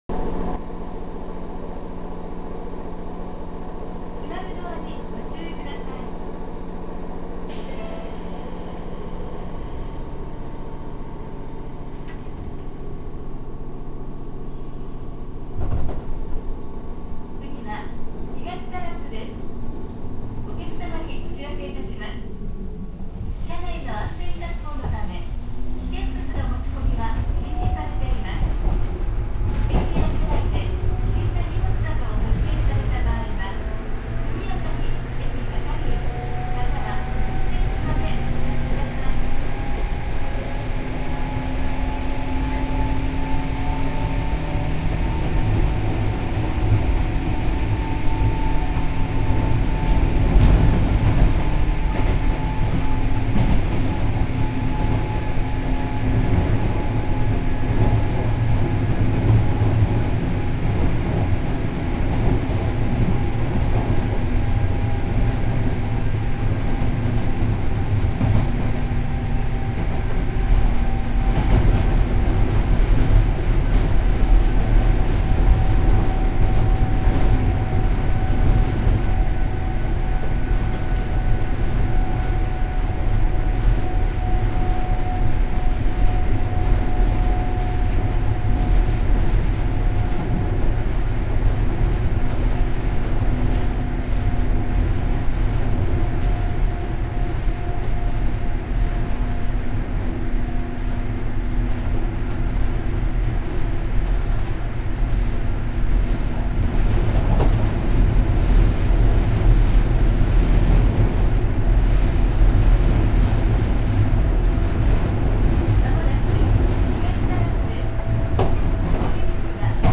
・103系1500番台（高音モーター）走行音
1500番台は、音の面ではブレーキ解除音とドア開閉音が201系のものに変わっていますが、走行音自体は103系のまま、変わっていません。
あまり速度は出さないもののそれほど混まないので収録環境は悪くありません。